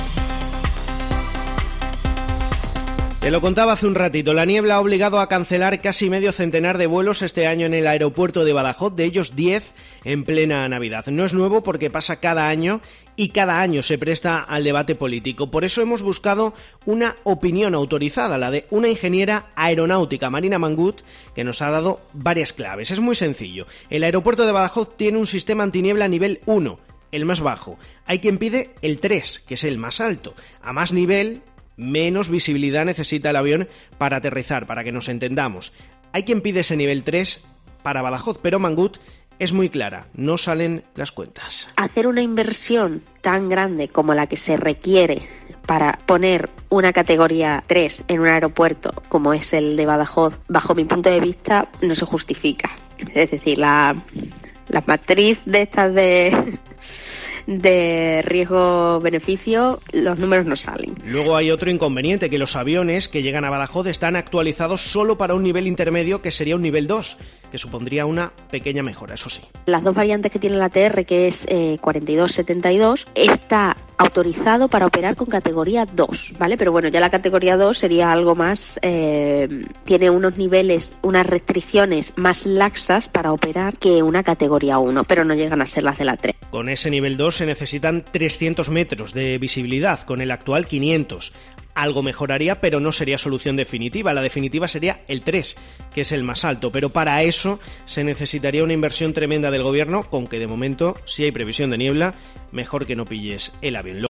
Analizamos con una Ingeniera Aeronáutica las claves de la situación y despejamos la duda sobre si es posible o no mejorar el sistema antiniebla